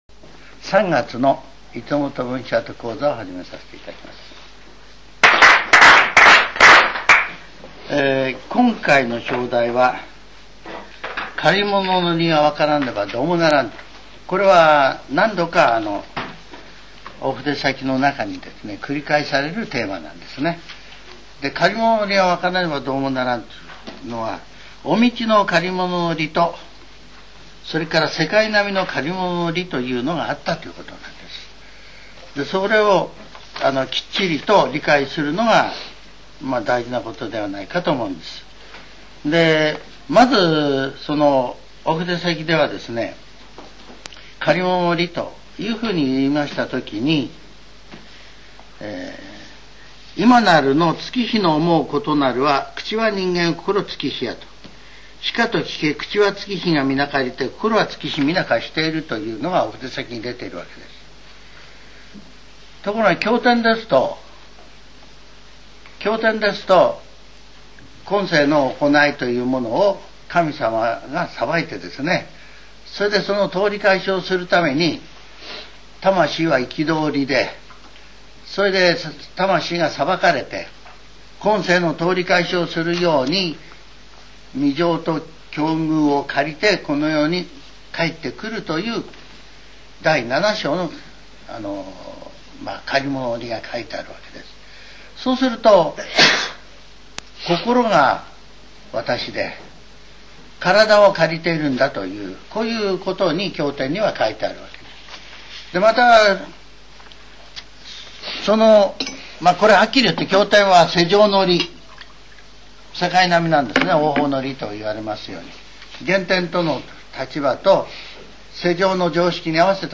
全70曲中42曲目 ジャンル: Speech